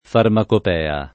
[ farmakop $ a ]